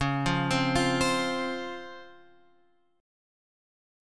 C# Major 7th